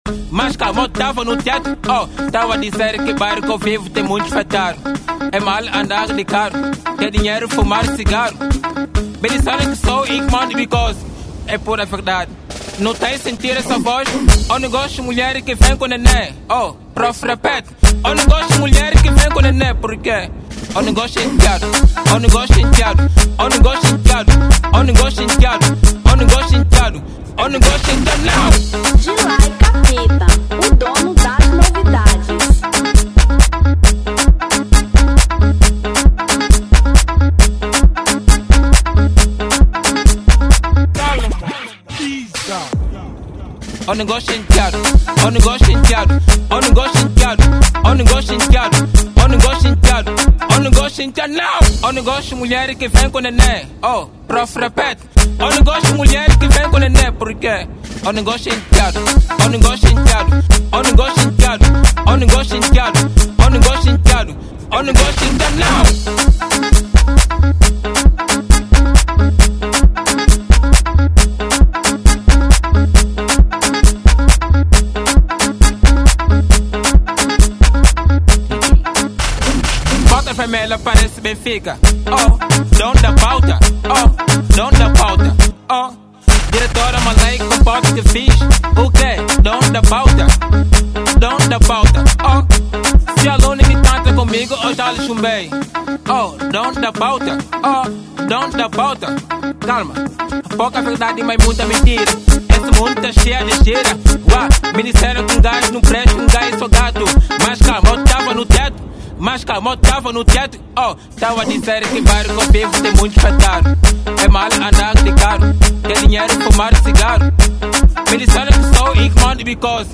Afro Edit 2025